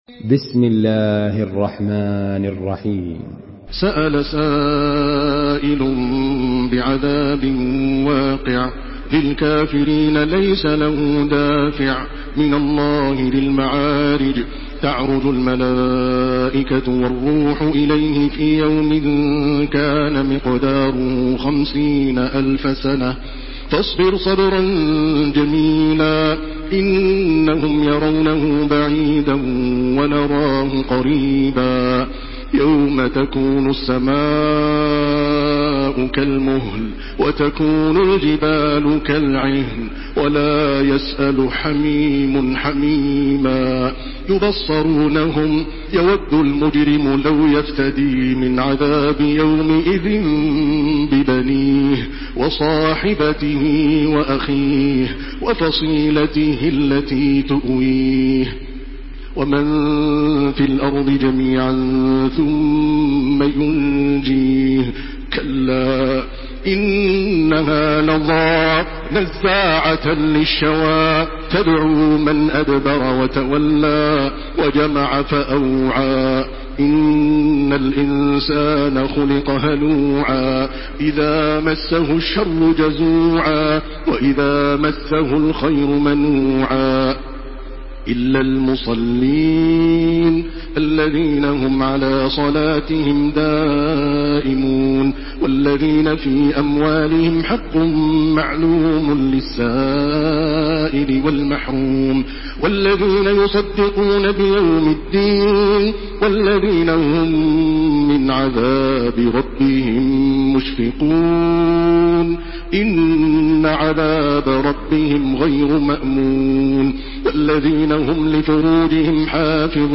Surah Al-Maarij MP3 by Makkah Taraweeh 1429 in Hafs An Asim narration.
Murattal